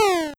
LakituThrow.wav